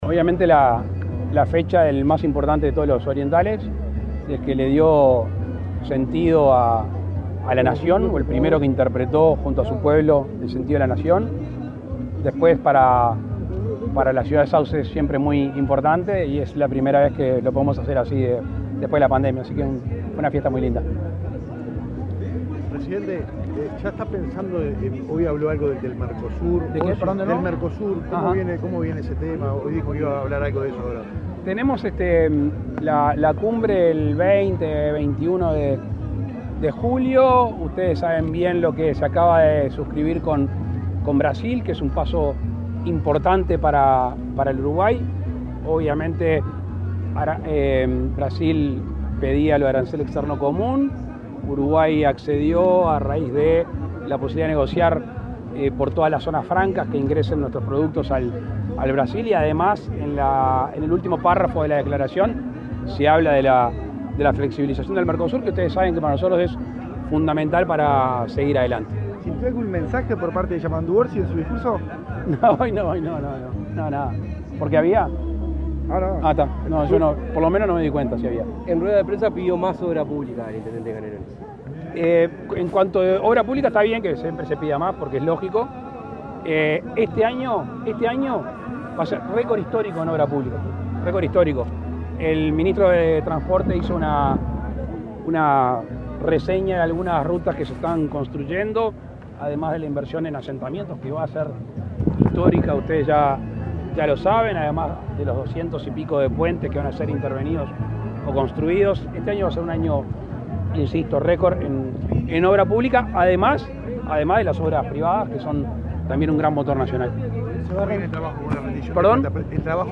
Declaraciones del presidente Lacalle Pou a la prensa
Declaraciones del presidente Lacalle Pou a la prensa 19/06/2022 Compartir Facebook X Copiar enlace WhatsApp LinkedIn El presidente Luis Lacalle Pou encabezó el acto conmemorativo del 258.° aniversario del natalicio del gral. José Artigas, realizado en la localidad de Sauce, Canelones. Luego, dialogó con la prensa.